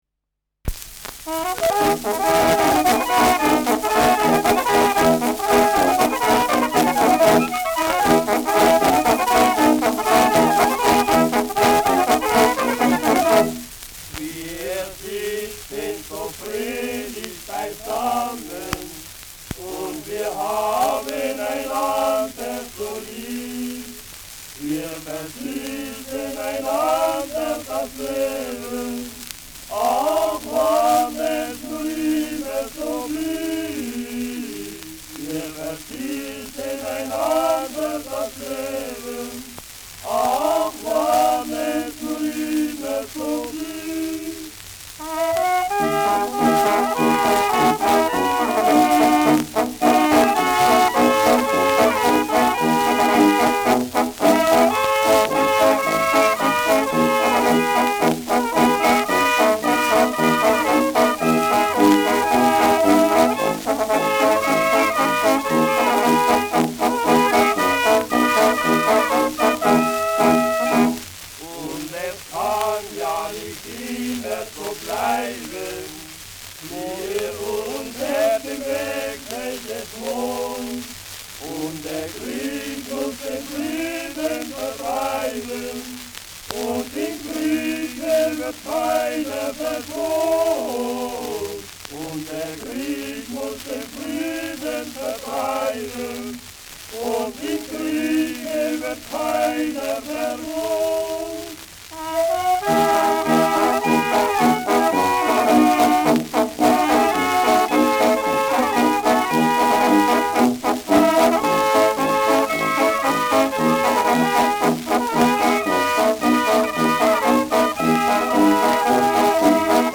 Schellackplatte
Tonrille: graue Rillen : leichte Kratzer
präsentes Rauschen : präsentes Knistern : abgespielt : leichtes Leiern